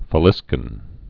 (fə-lĭskən)